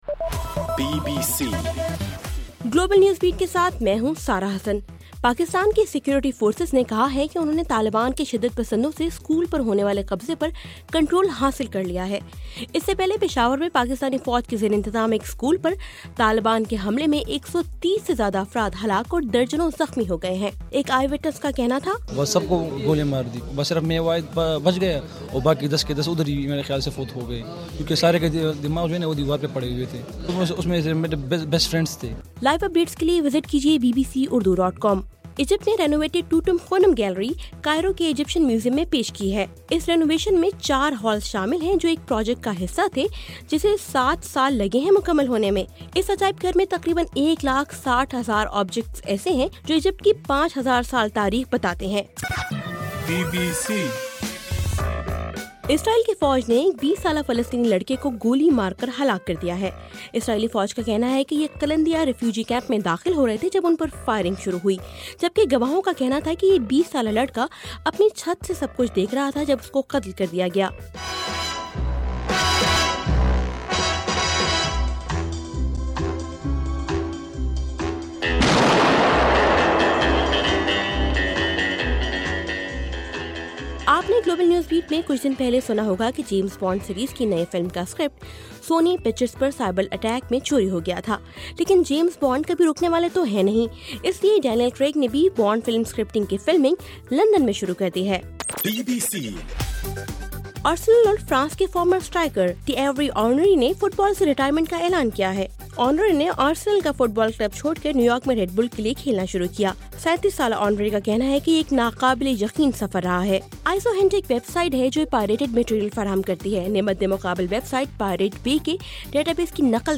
دسمبر 16: رات 9 بجے کا گلوبل نیوز بیٹ بُلیٹن